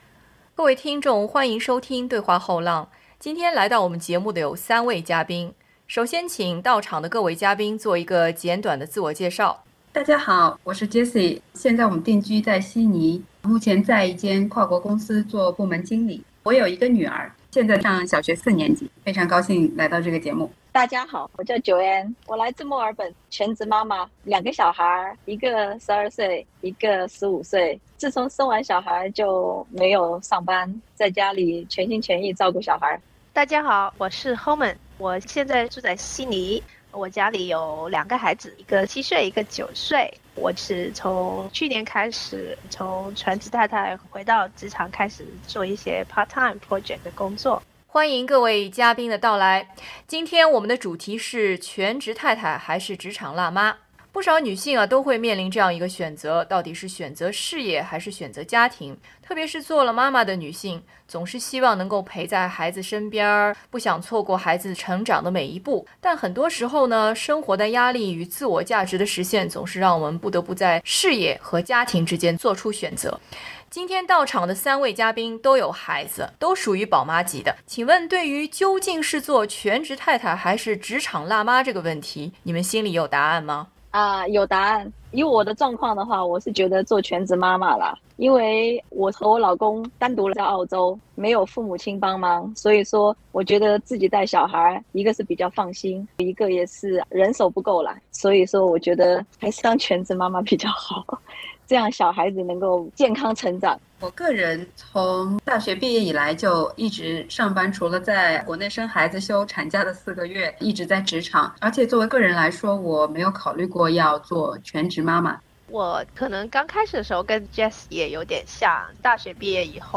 本期【对话后浪】为您请来了三位嘉宾，一位是全职太太，一位是职场辣妈，一位是由职场辣妈转为全职太太又重新做回职场辣妈的女性。